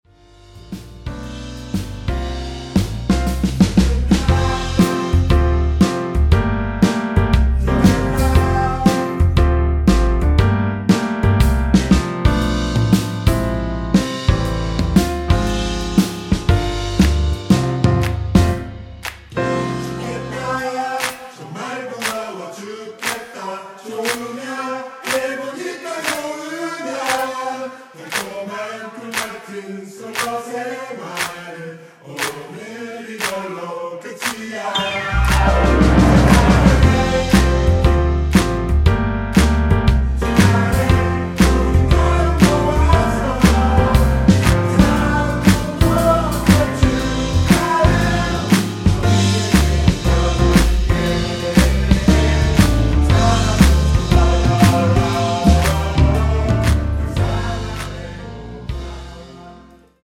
원키에서(-2)내린 코러스 포함된 MR 이며 코러스 추가된 부분은 가사 부분 참조 하세요!
앞부분30초, 뒷부분30초씩 편집해서 올려 드리고 있습니다.
중간에 음이 끈어지고 다시 나오는 이유는